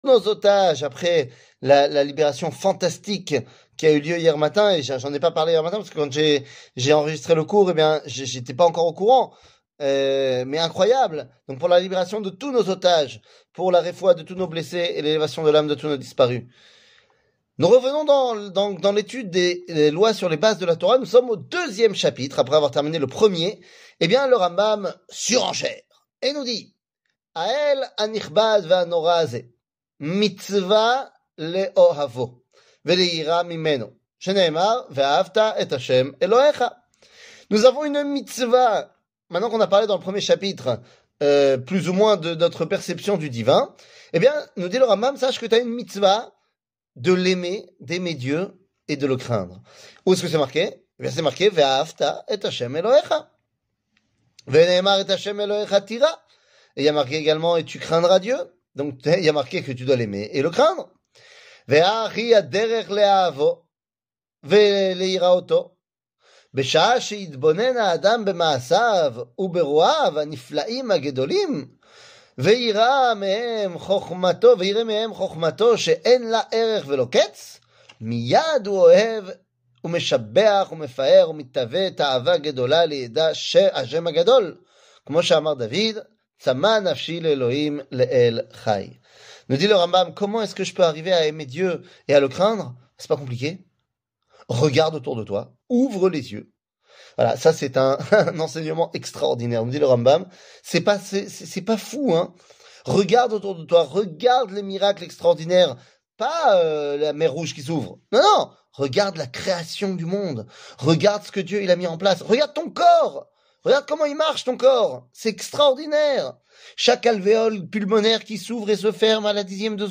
שיעור מ 13 פברואר 2024